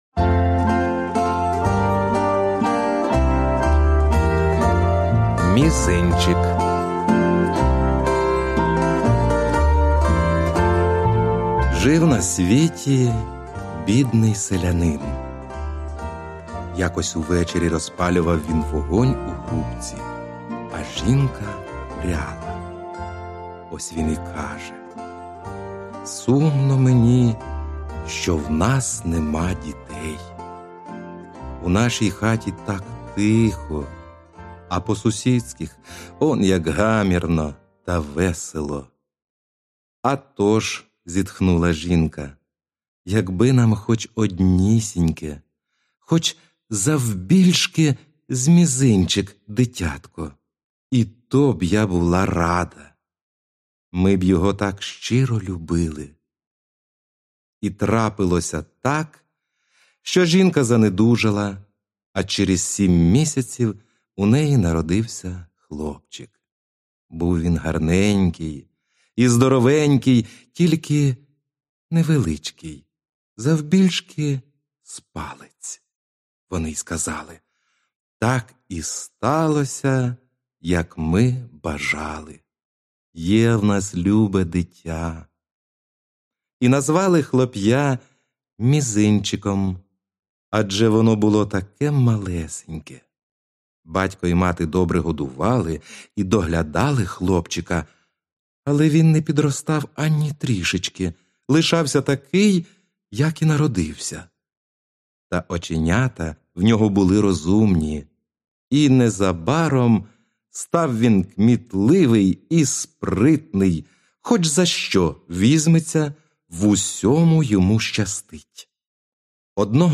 Аудіоказка Мізинчик